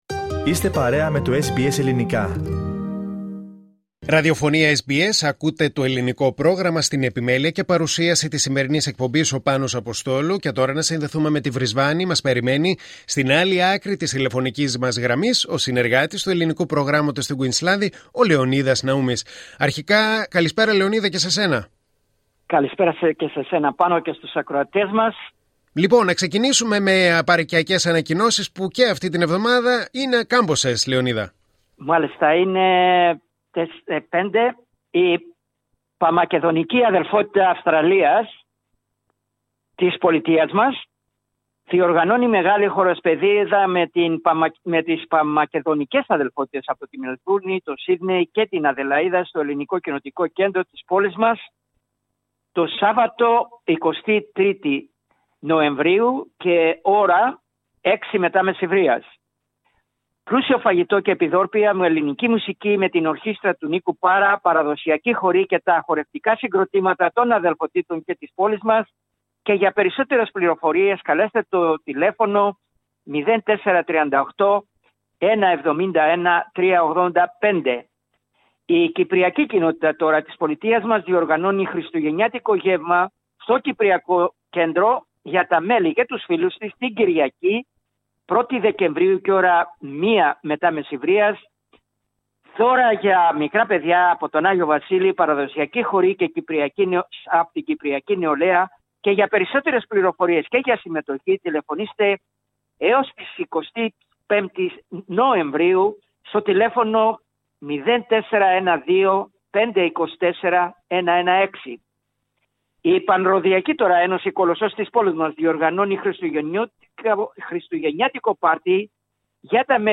Περισσότερα στην ανταπόκριση